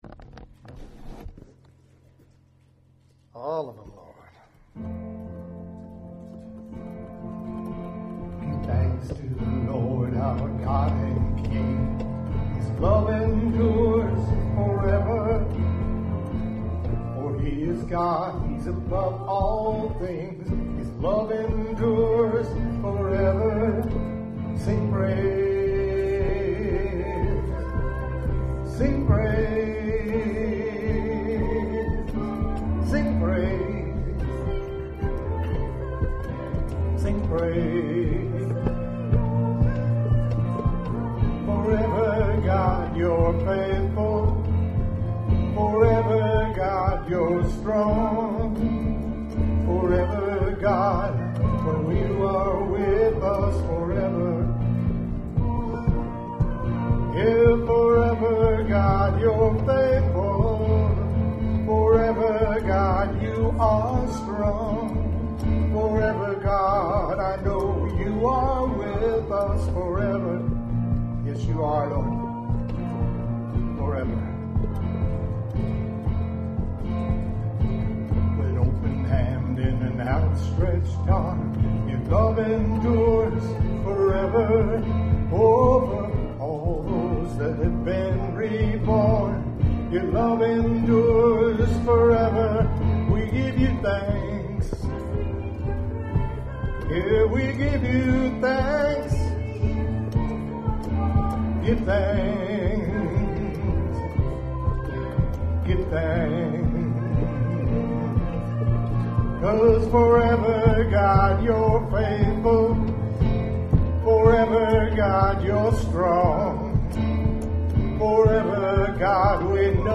WORSHIP1130.mp3